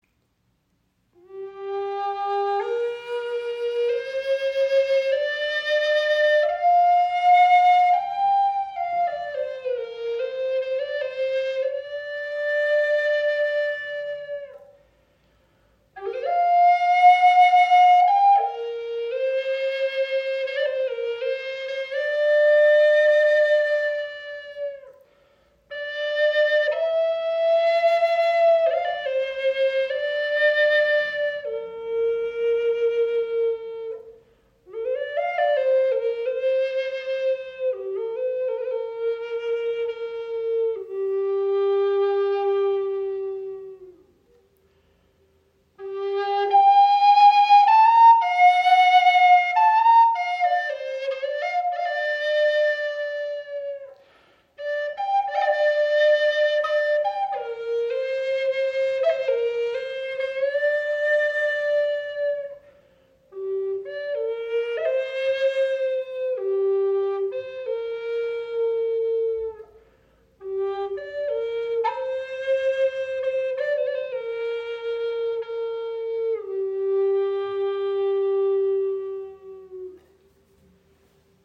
Tanoak G-Moll Flöte – klare Stimme aus amerikanischem Hartholz
• Icon Ausdrucksstarker, klarer Klang mit brillanten Höhen
Dieses selten verarbeitete, heimische Hartholz verleiht der Flöte eine klare, leuchtende Stimme mit brillanter Tonreinheit, besonders in den hohen Lagen.
Der warm-mitteltiefe Klang dieser Flöte schafft eine emotionale Verbindung, die das Herz berührt.
High Spirits Flöten sind Native American Style Flutes.